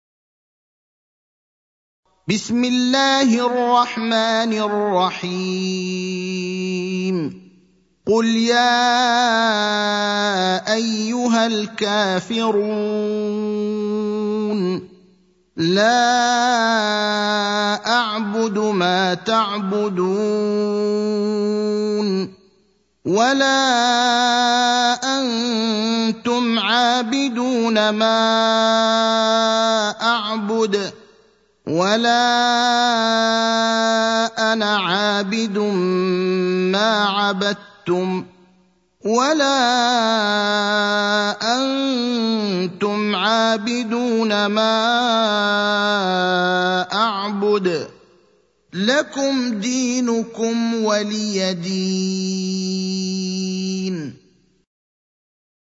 المكان: المسجد النبوي الشيخ: فضيلة الشيخ إبراهيم الأخضر فضيلة الشيخ إبراهيم الأخضر الكافرون (109)